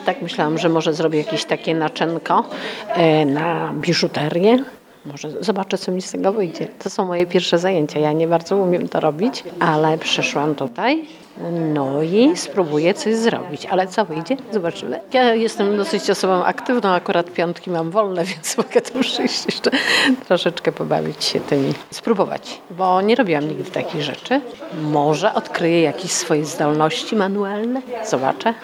uczestniczka warsztatów